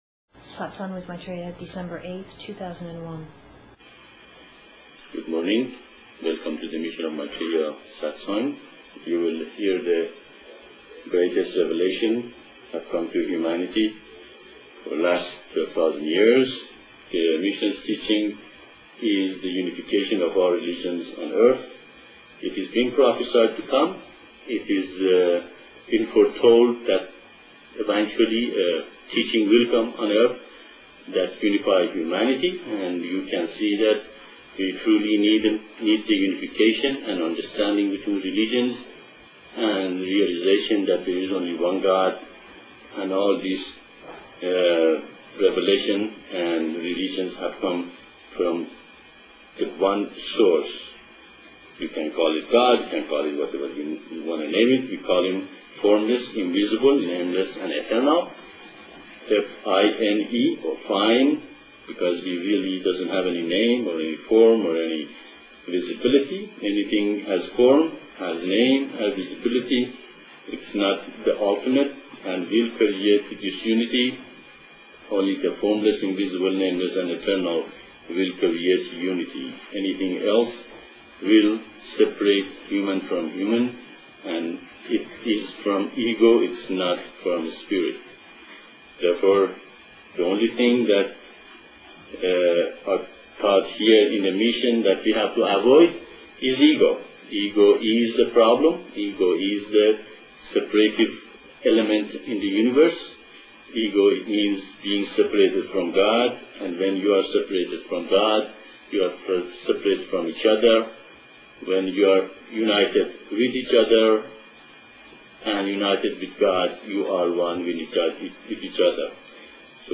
12/08/01 Satsang (Discourse)